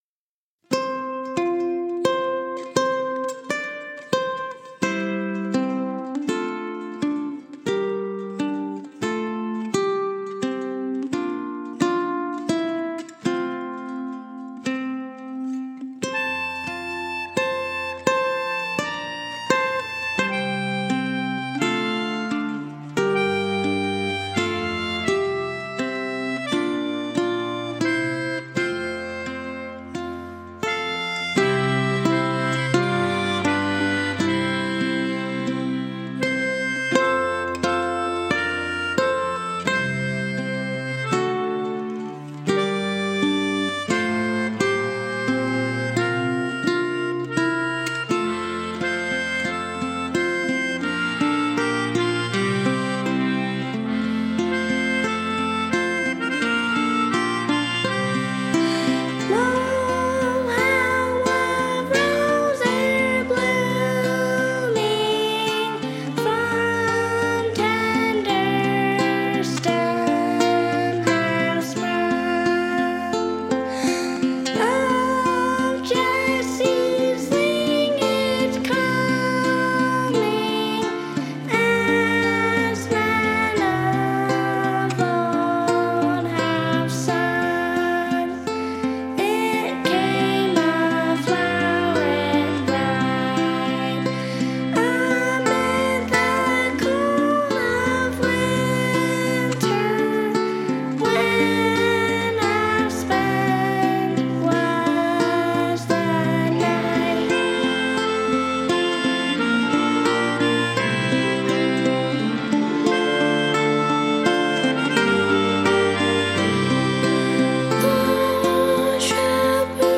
German carol
ukulele, melodica, autoharp, bass
accordion
vocals
It has a haunting melody with an unusual meter.